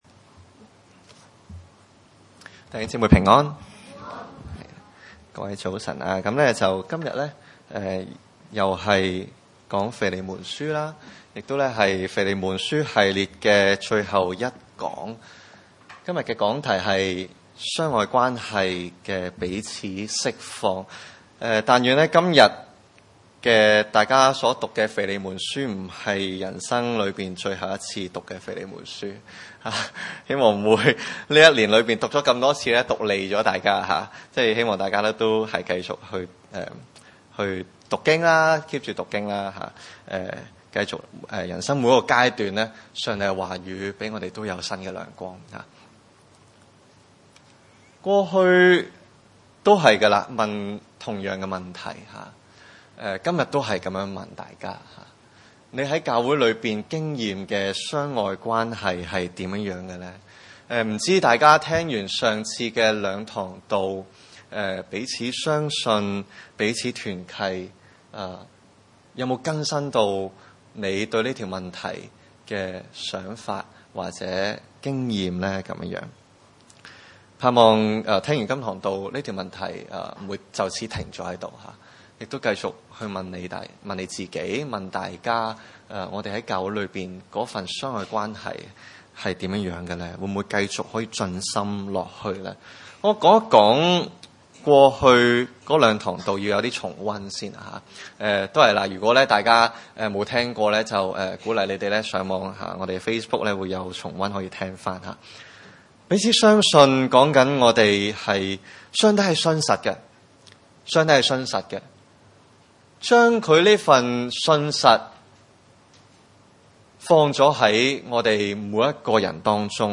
經文: 腓利門書第1章1-25節 崇拜類別: 主日午堂崇拜 1.